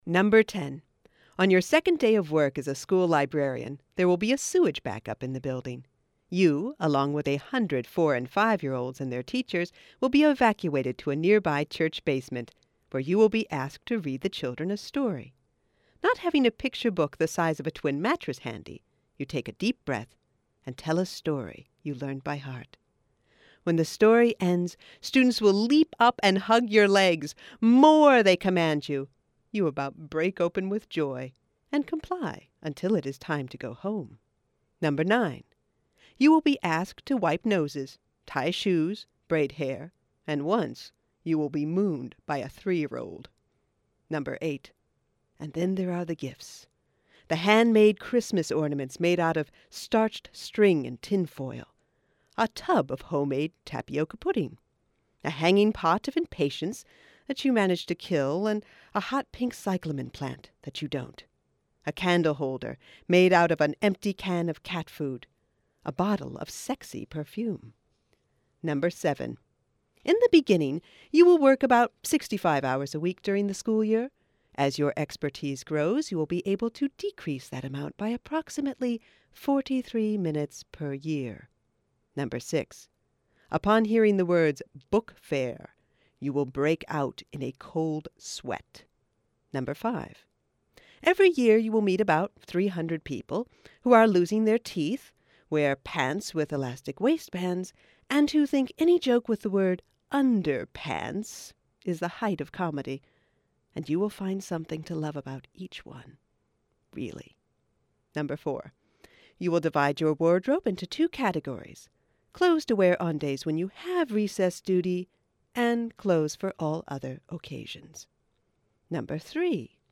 Originally aired on WMUB-FM in Oxford, Ohio (2007)